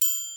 Tm8_HatxPerc24.wav